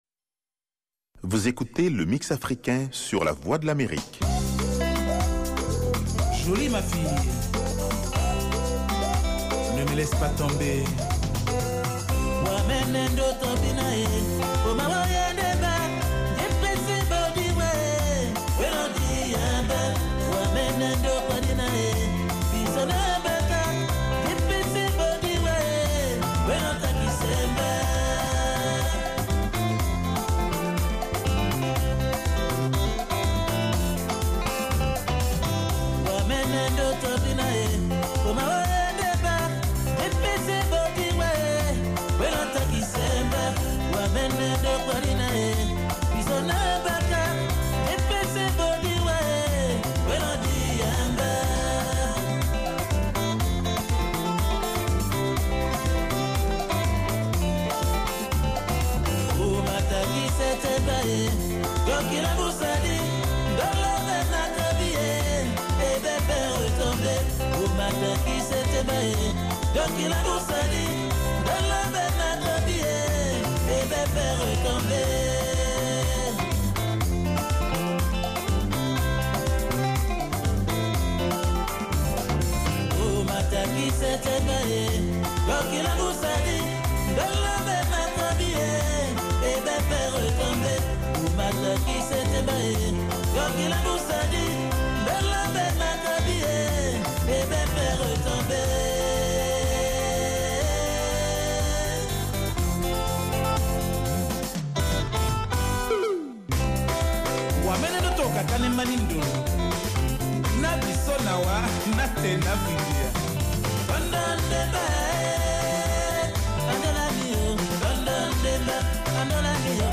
Emission quotidienne de musique et d’entretien avec les auditeurs.